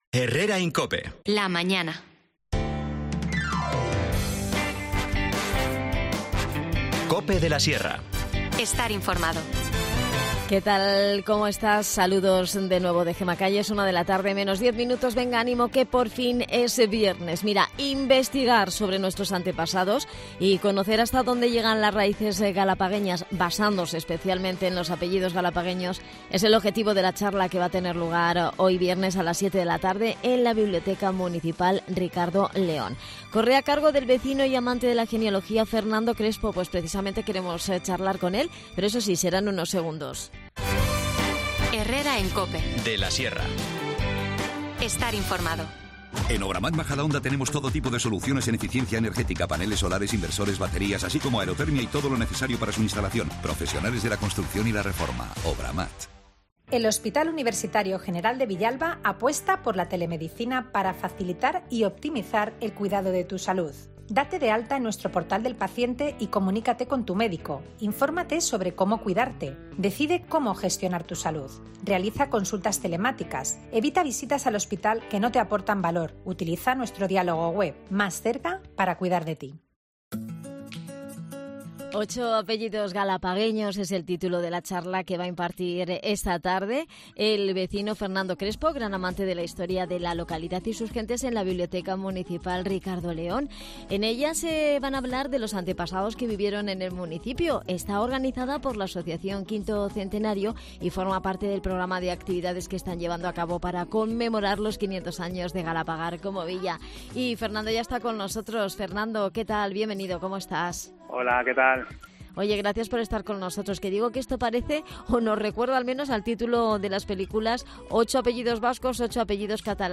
Charlamos
Las desconexiones locales son espacios de 10 minutos de duración que se emiten en COPE, de lunes a viernes.